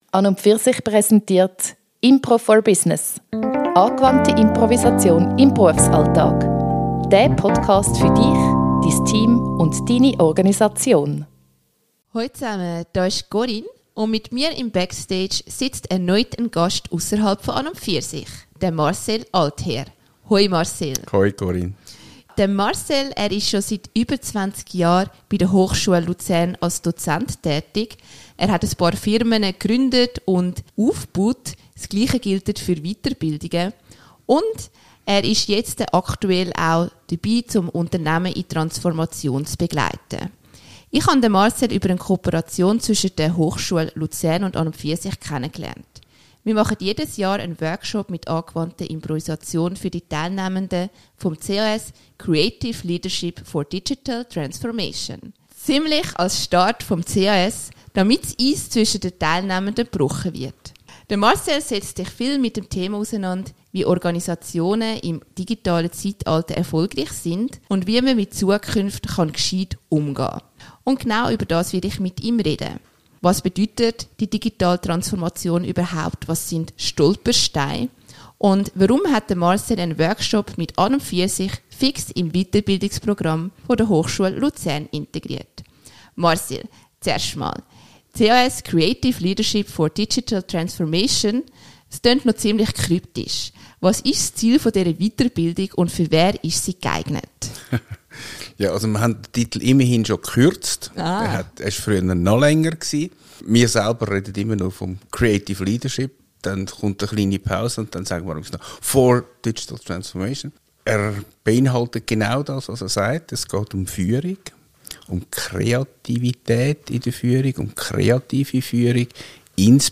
Er berät zudem Unternehmen im digitalen Wandel. Im Interview erzählt er, was Chancen und Stolpersteine beim digitalen Wandel sind und wie Organisationen mit Futures Literacy die Zukunft aktiv gestalten versuchen können.